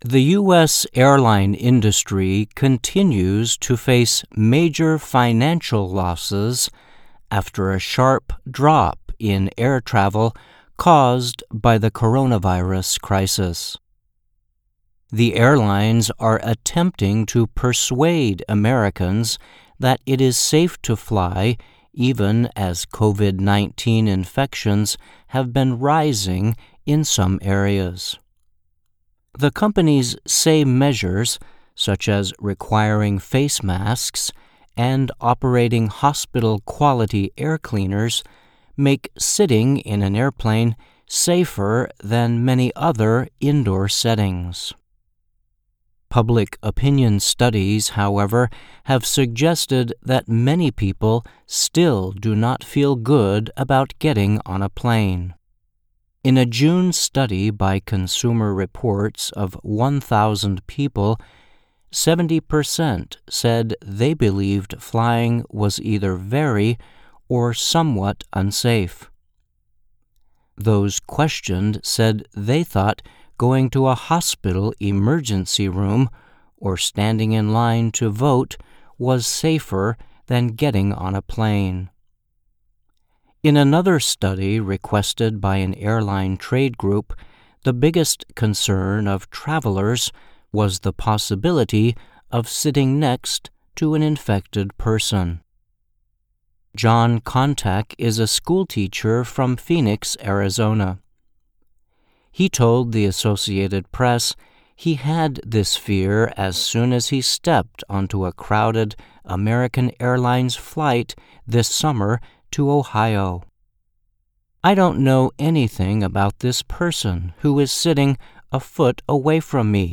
慢速英语:航空公司难以说服公众乘坐飞机